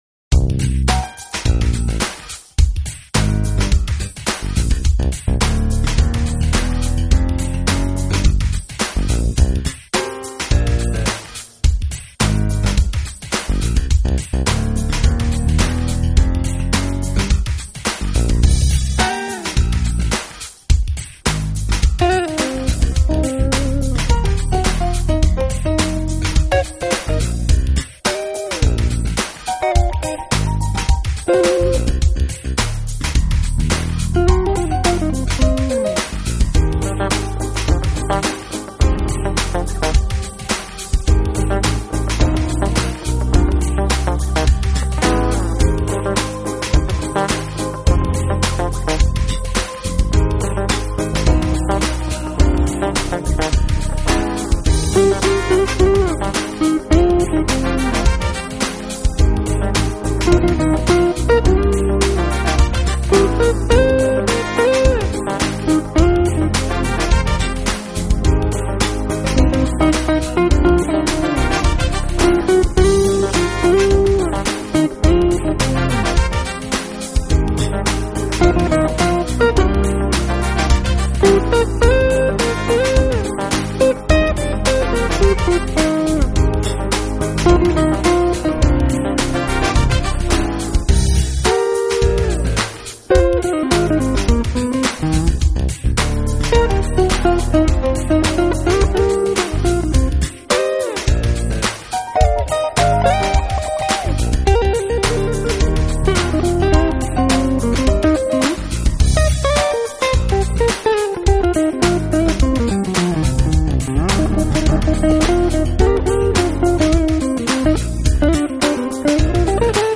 【爵士吉他】
节奏欢快，旋律优美。